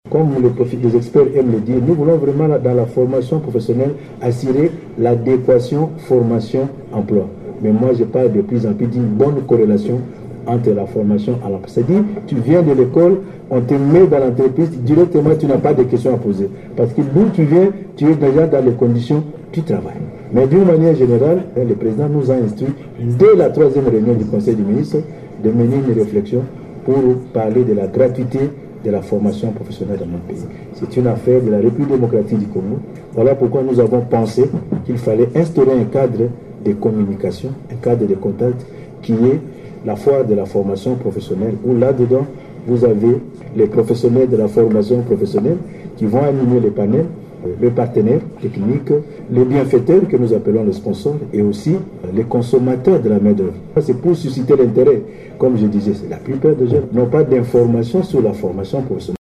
Il l’a dit lors du briefing de presse coanimé avec son collègue de la Communication et Médias, Patrick Muyaya à Kinshasa.